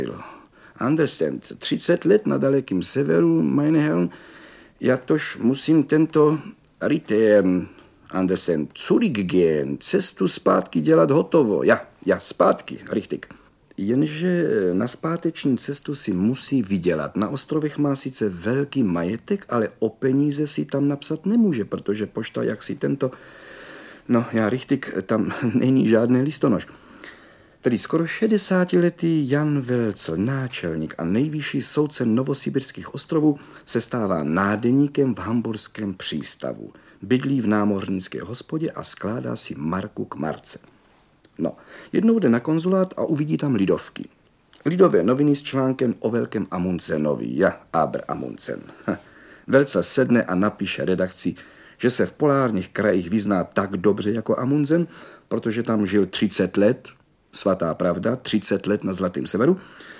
Audiobook
Read: Karel Höger